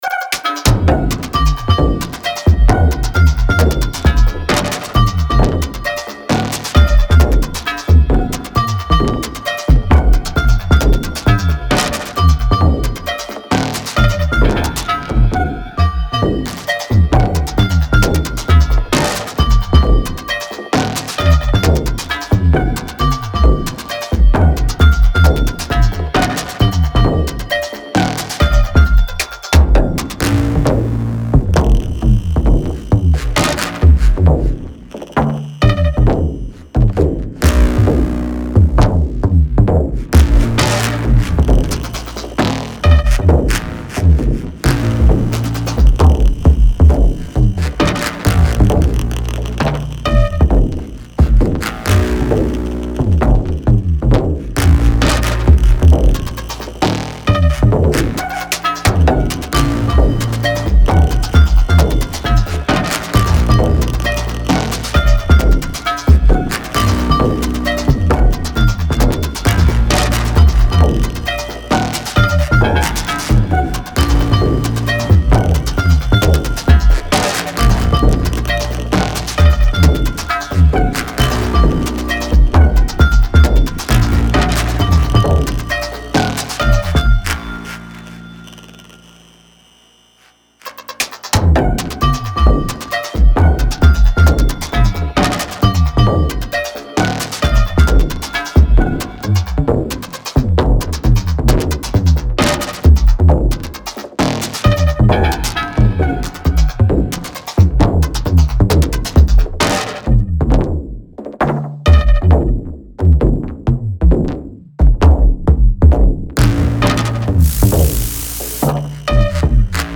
Всё синтезаторы собраны из обработок. В основе либо обычный квадратный тригер либо осциллятор на основе кольцевой модуляции.